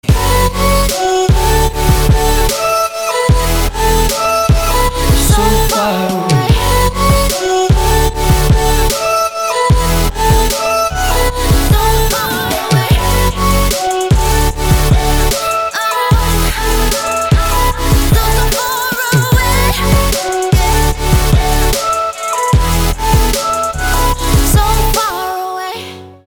• Качество: 320, Stereo
мужской голос
громкие
женский вокал
dance
Electronic
электронная музыка
future bass
Future Pop
Стиль: future bass